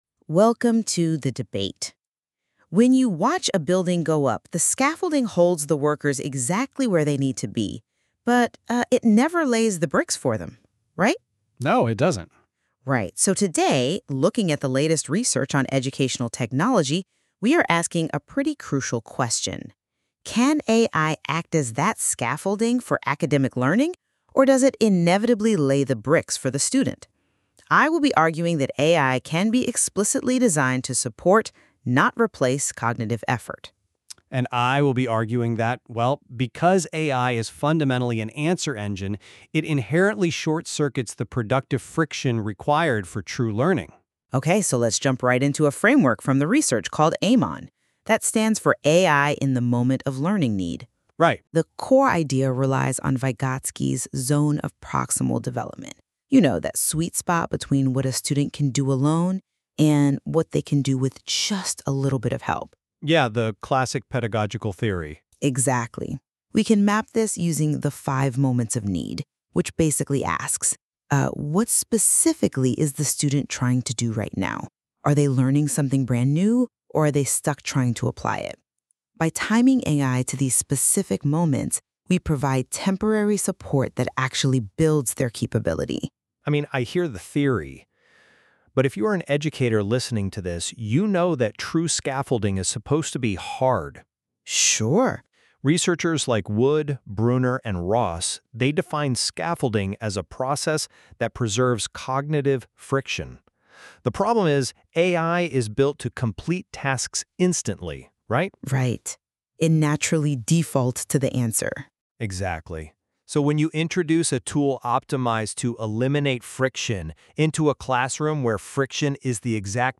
Here is a short (5 min) debate on the principles created using NotebookLM based on this blog post.